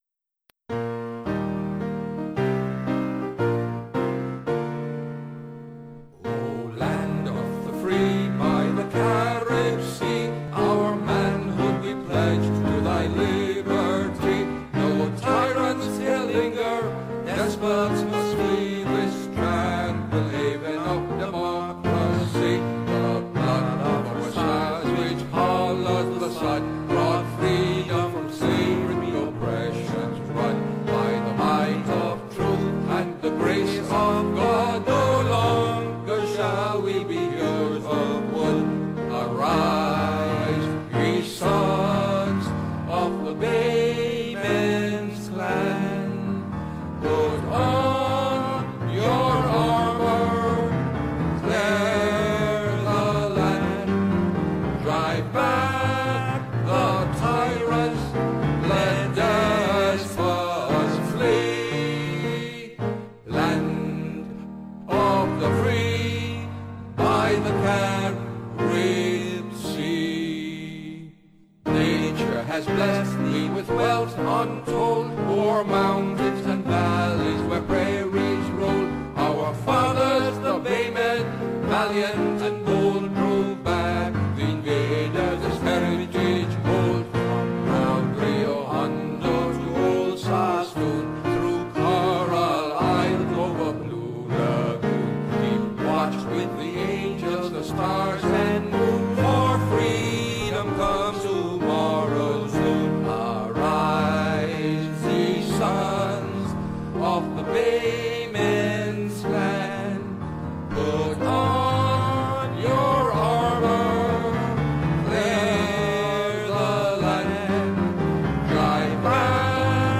National Anthem of Belize (As Sung by George Cadle Price
National-Anthem-of-Belize-sang-by-George-Price.wav